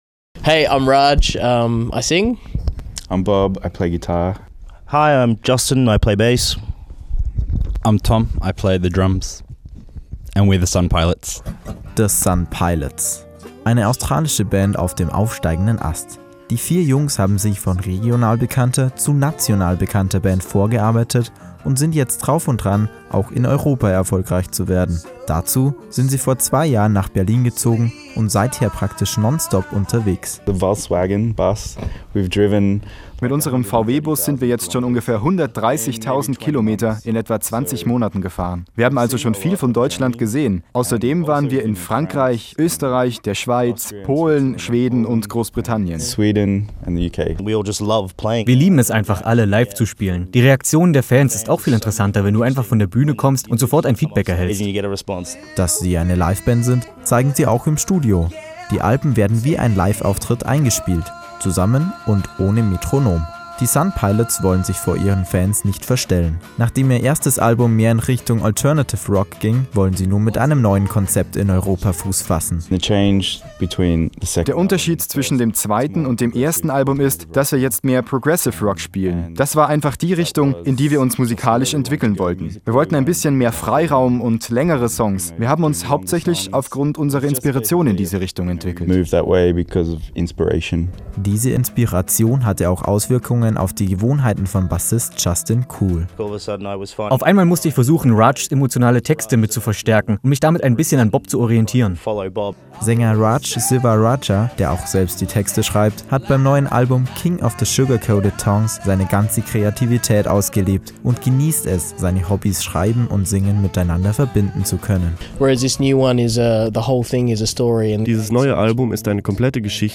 Wir haben eine Band getroffen, die sich stilistisch an viele bekannte Rockbands anlehnt und doch etwas ganz eigenes daraus macht.